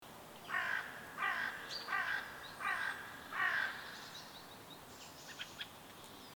Crow Screaming Bird 02 Bouton sonore